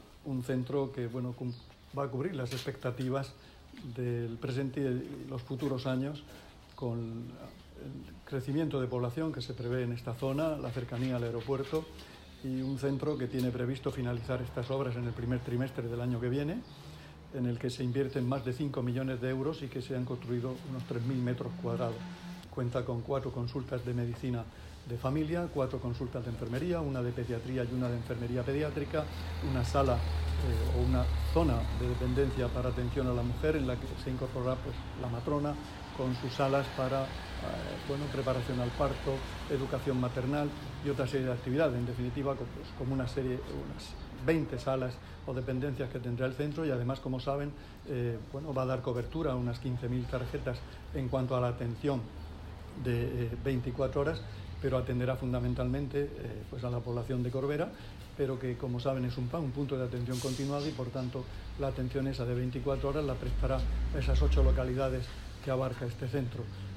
Declaraciones del consejero de Salud sobre el nuevo centro de salud de Corvera